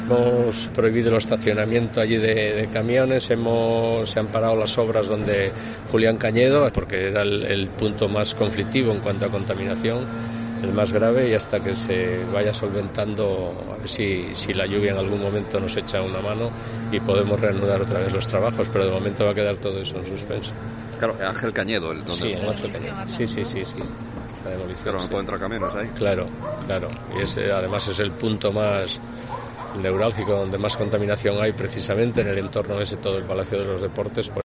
José Ramón Prado, concejal de Seguridad Ciudadana de Oviedo